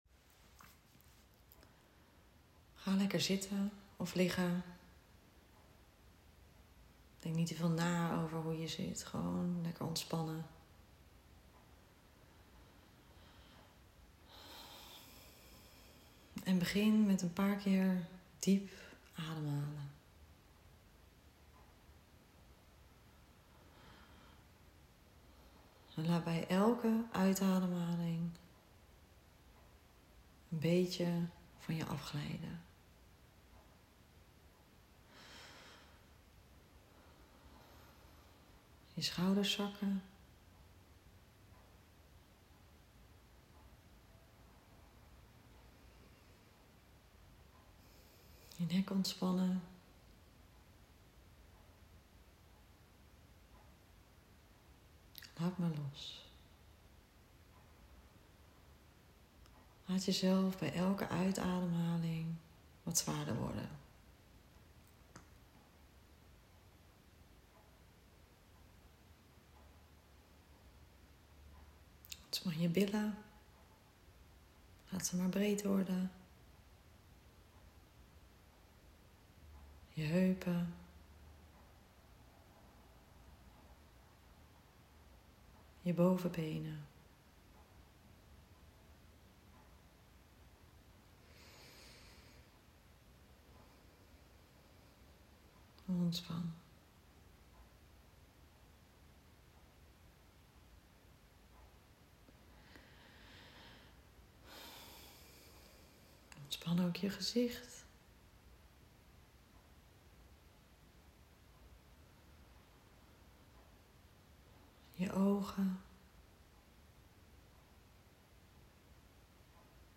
Experience guided meditation as a path to inner freedom and love. Reconnect with your true self and awaken the peace that has always lived within you.